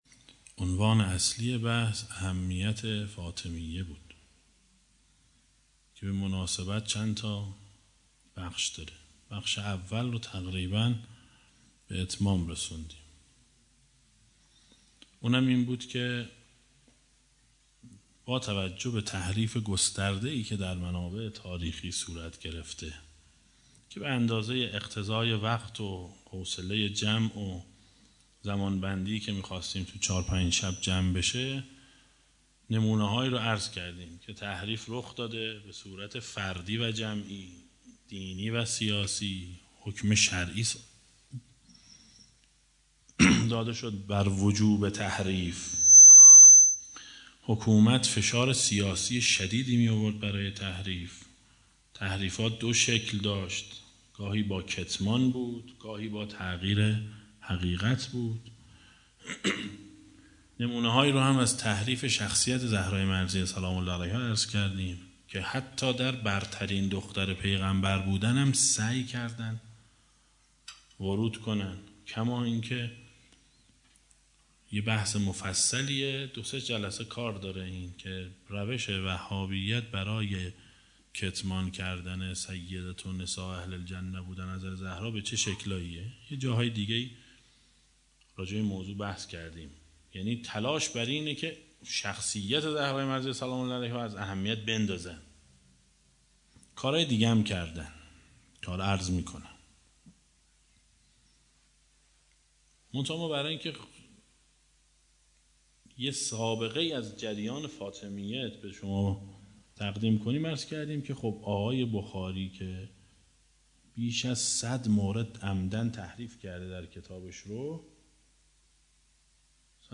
صوت این سخنرانی که در پنج شب اول دهه اول فاطمیه امسال در حسینیه محبان اهل بیت(ع) ایراد شده است آمده است: